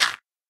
minecraft / sounds / dig / gravel1.ogg
gravel1.ogg